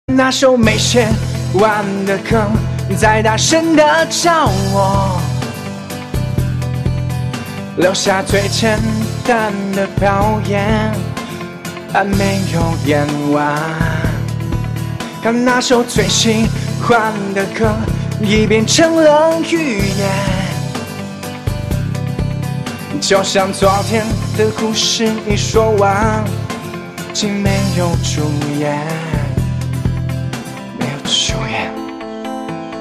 M4R铃声, MP3铃声, 华语歌曲 46 首发日期：2018-05-15 11:18 星期二